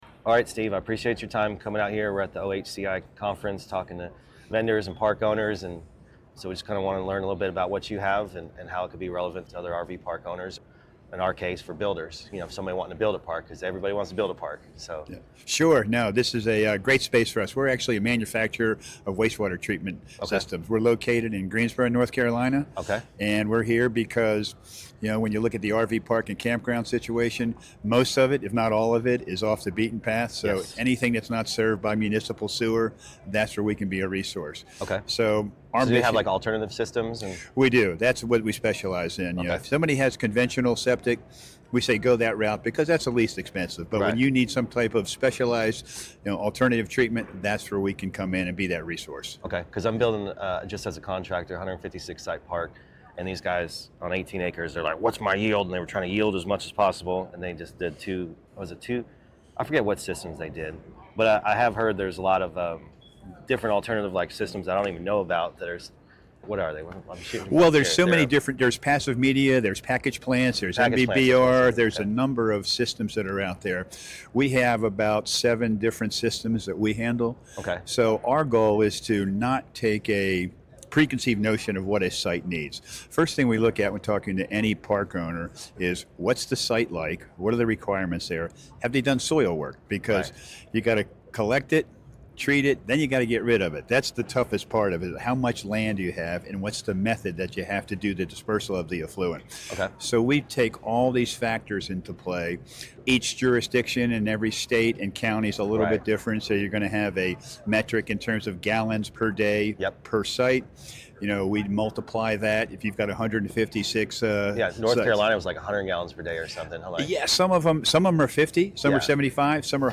• (00:08:03) - Interviewing Anua Robotics